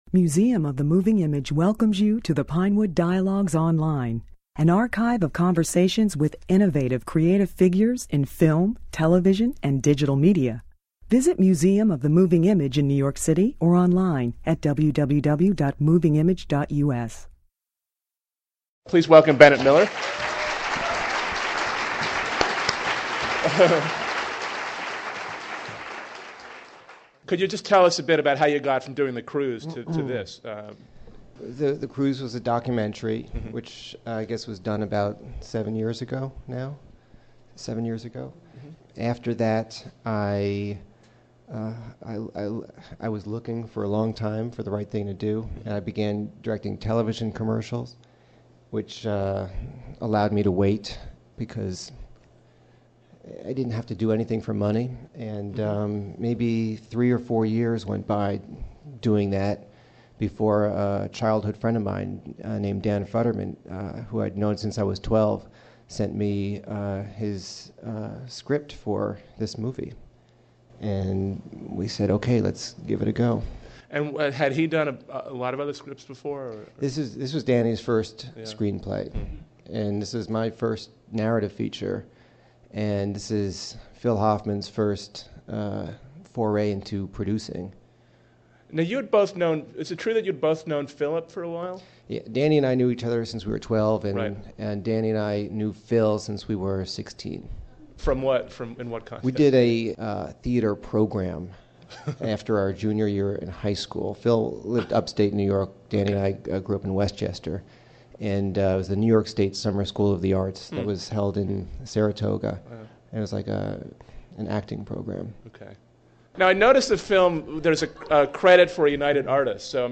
Bennett Miller January 7, 2006 Capote is an astonishing fiction-film debut for Bennett Miller, who spoke at Moving Image the day the film was chosen as Best Picture by the National Society of Film Critics. Miller discusses his collaboration with his longtime friends Dan Futterman (who wrote the screenplay) and Philip Seymour Hoffman (who won an Oscar for his moving portrayal of Truman Capote). He also talks about his fascination with the inevitability of Capote's decline following the success of In Cold Blood .